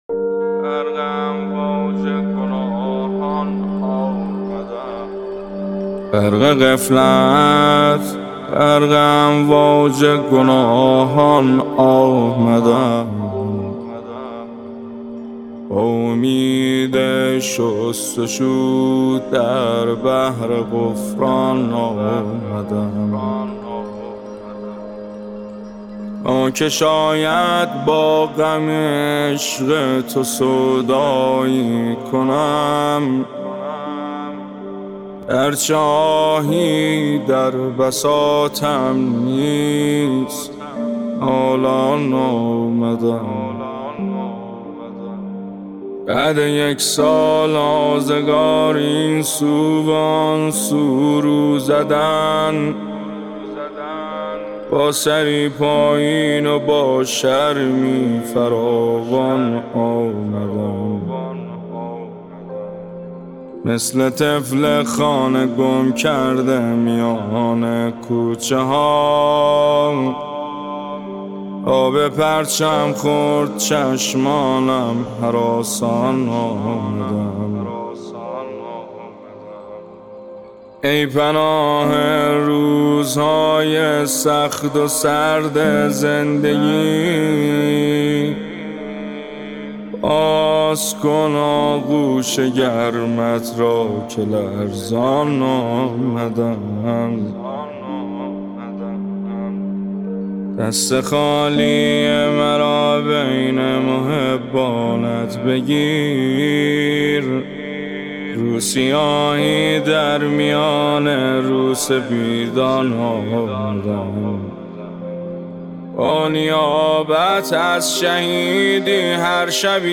نماهنگ مهدوی دلنشین